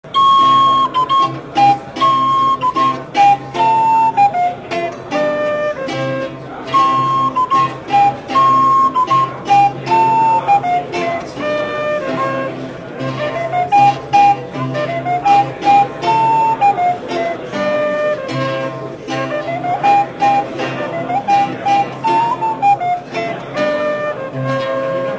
Az alábbi hangfelvételek az asztalon látható MiniDisc profi hangrögzítőre készültek, de messze nem stúdió körülmények között (hallatszanak a falatozás hangjai, edénycsörgés, beszéd a háttérben), és messze nem hivatásos hangmérnökök által. A hangászok sem pódiumhangversenyt adtak, ritkán előfordultak kisebb megbicsaklások és a végére a lant is kissé lehangolódott, de sebaj.
A publikálhatóságuk (minél kisebb kis méret) érdekében tovább kellett rontani a felvétel minőségén is.